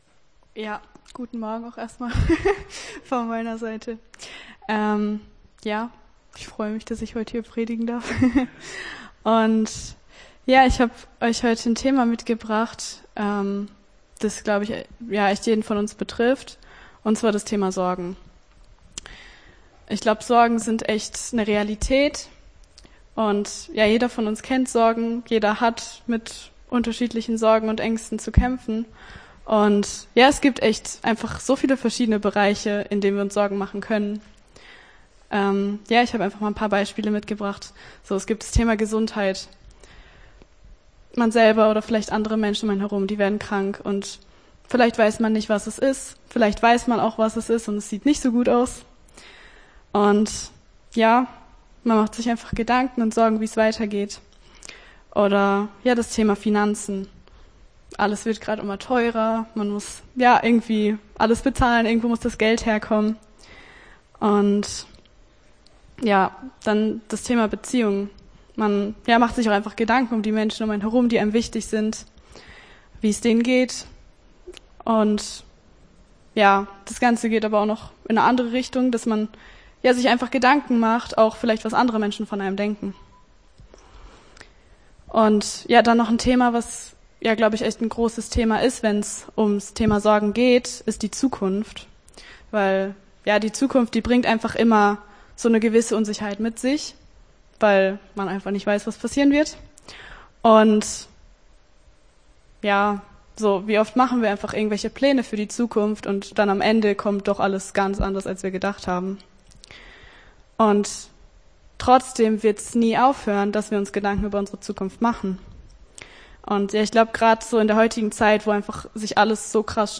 Gottesdienst 21.07.24 - FCG Hagen